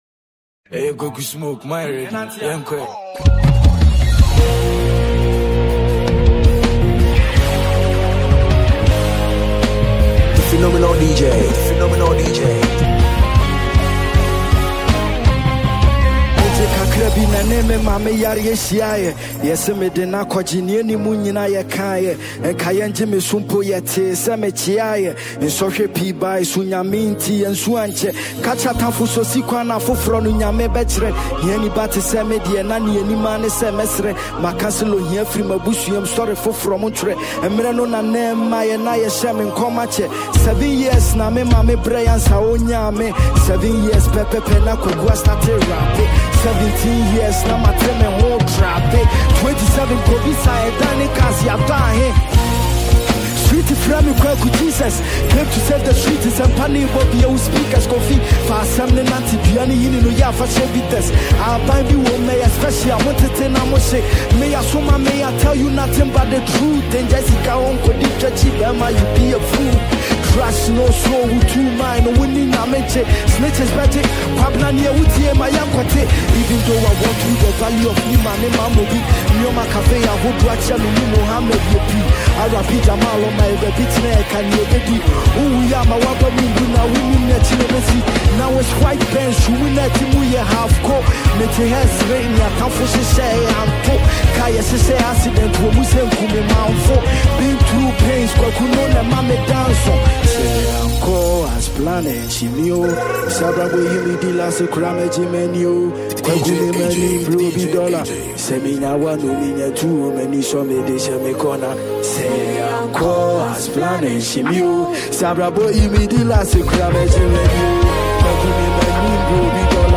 a dope freemix for all.